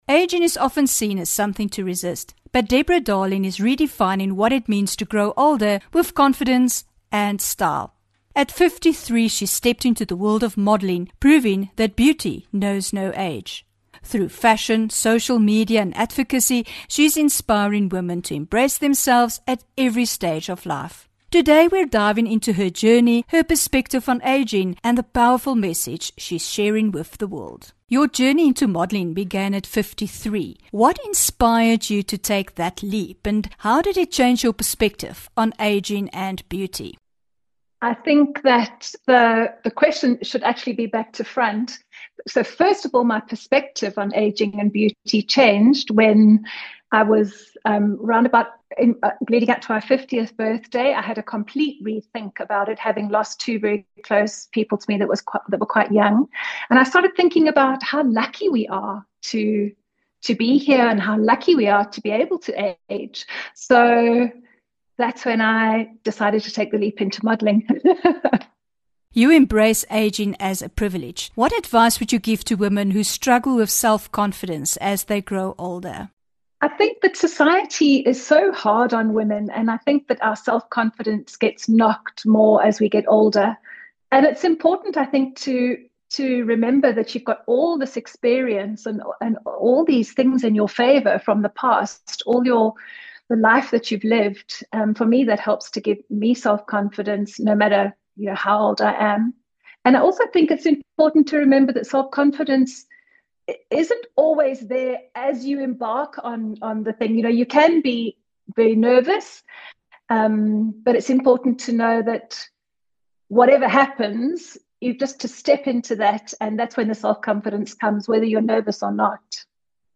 18 Feb INTERVIEW